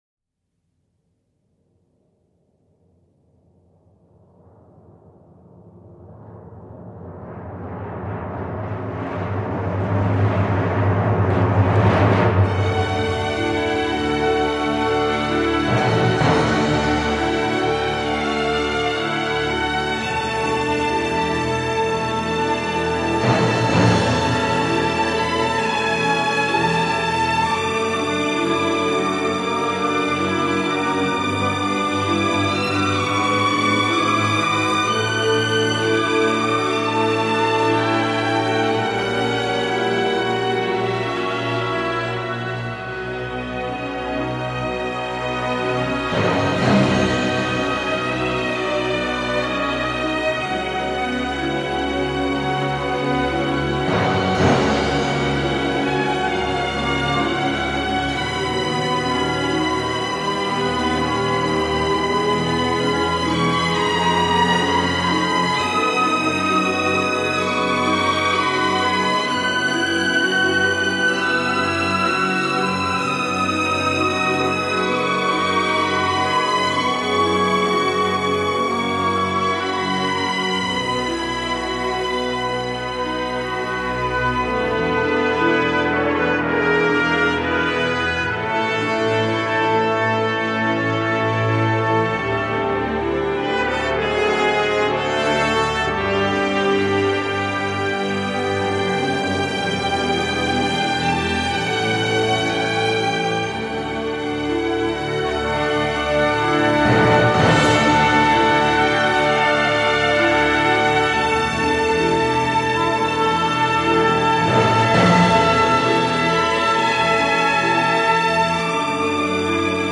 avec des chœurs en plus.
morceaux jazzy, parfois avec une pointe de pop.